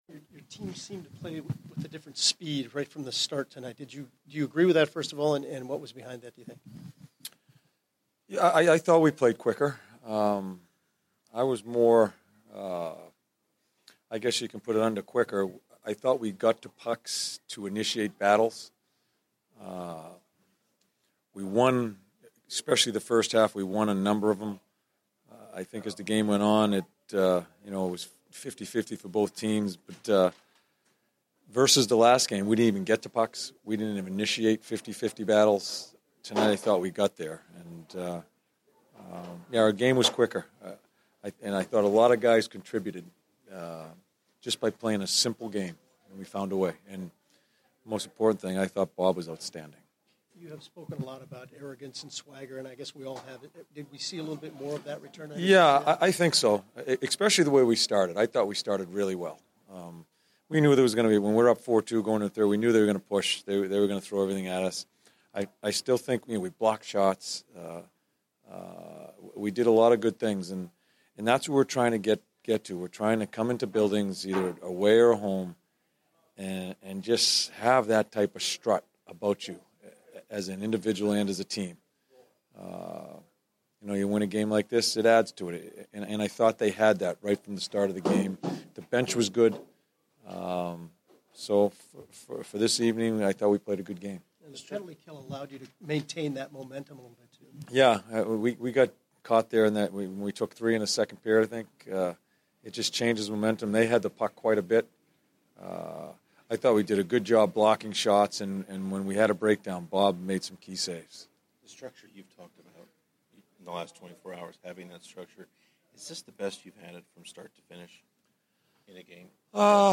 John Tortorella Post-Game 11/3/15